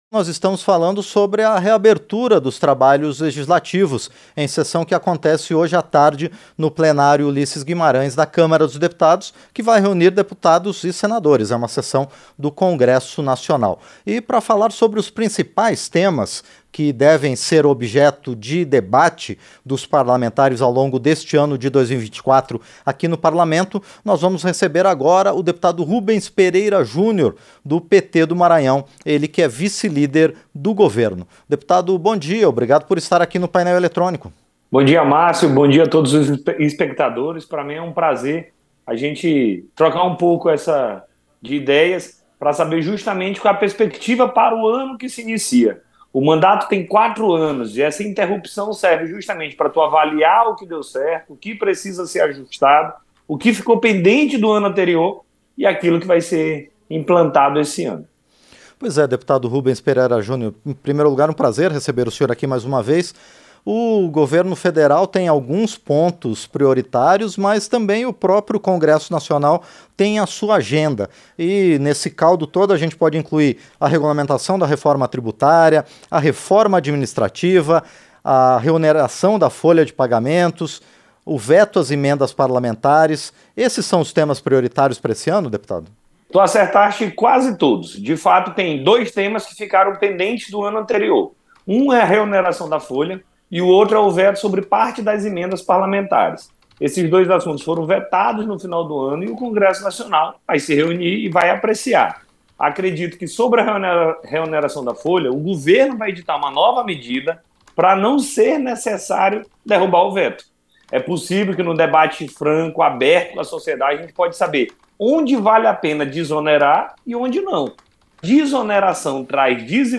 Entrevista - Dep. Rubens Pereira Júnior (PT-MA)